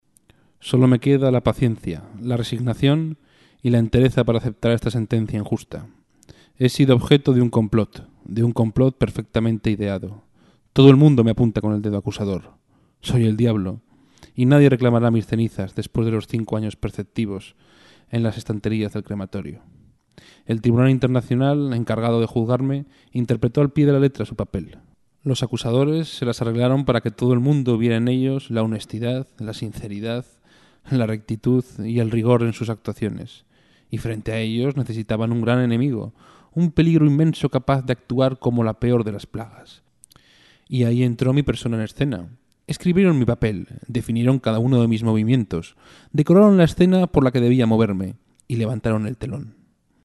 Voz con profundidad. Trabajo técnico e interpretativo
Kein Dialekt
Sprechprobe: Sonstiges (Muttersprache):